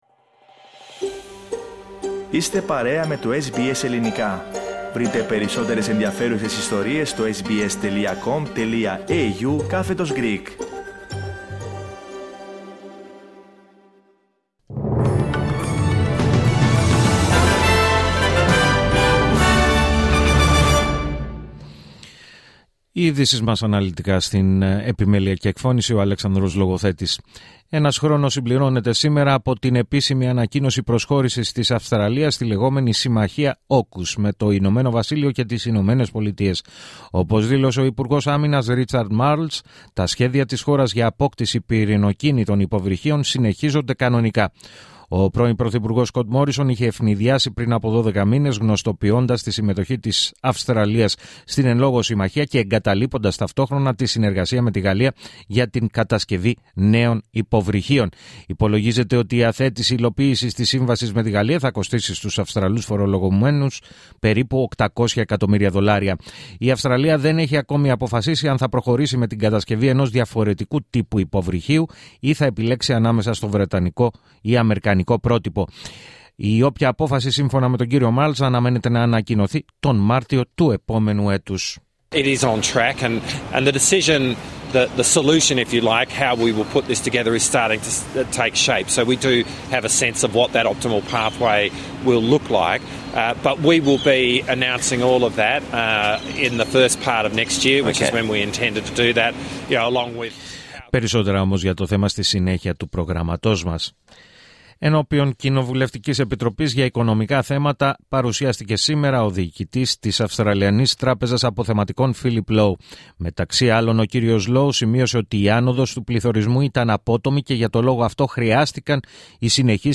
Το αναλυτικό δελτίο ειδήσεων του Ελληνικού Προγράμματος της ραδιοφωνίας SBS, στις 4 μ.μ.